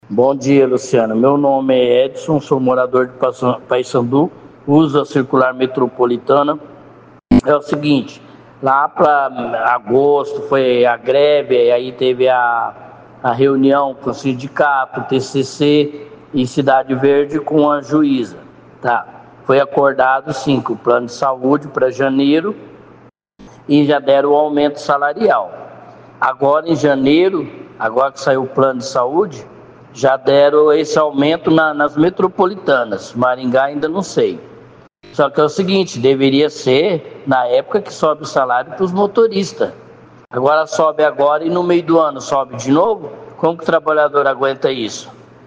O ouvinte da CBN